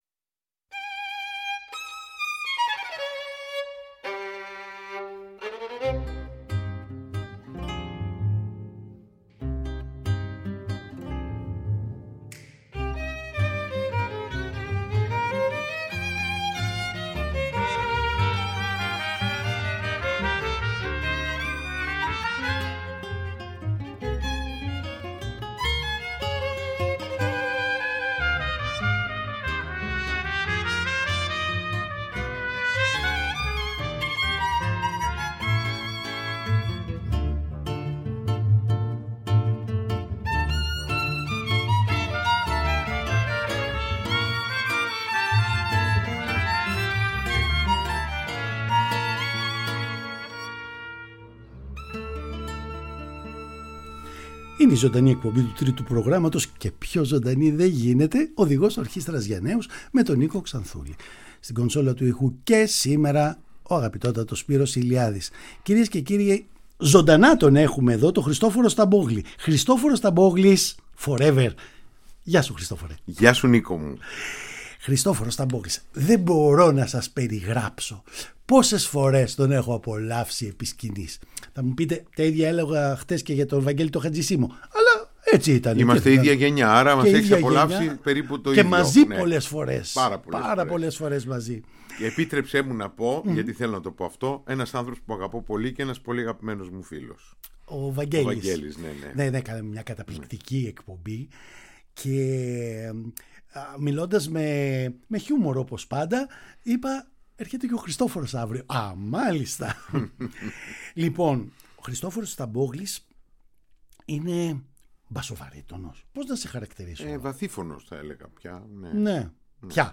Η πρόσκλησή μας είναι αφορμή να ακούσουμε υπέροχες ερμηνείες του από όμορφες στιγμές της πλούσιας καριέρας του.